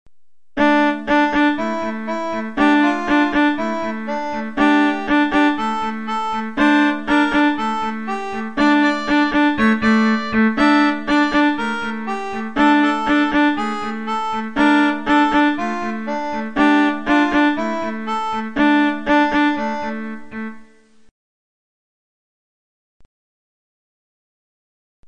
(földi) Útikalauz - Digitális népzene
Hely szűke miatt csak 32 Khz-es a minőség, de így is szépen szól.
(A lejátszáshoz a kiírás szerint Sound Blaster Live hangkártyát használtunk. Egyes zenéknél így is előfordulhat, hogy más, illetve másként szólal meg, mint a szerző remélte.)